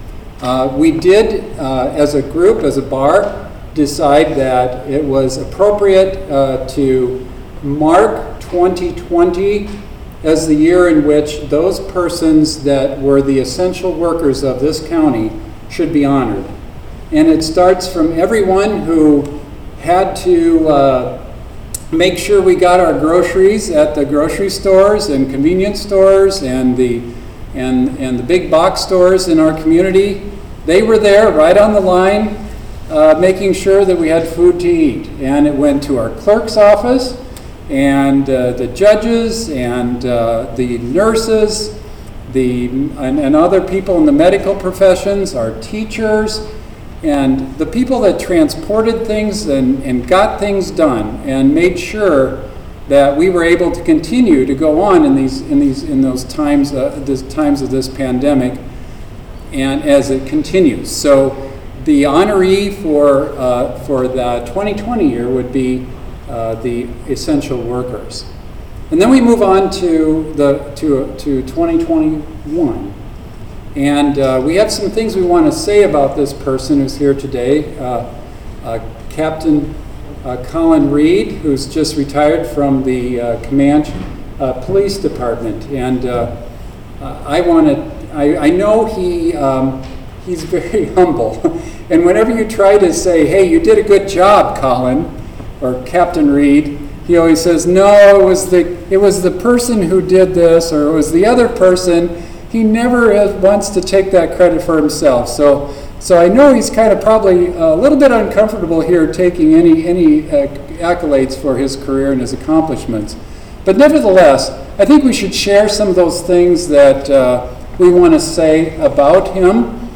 Clinton County Attorney Mike Wolf said the Liberty Bell Award started locally in 1969.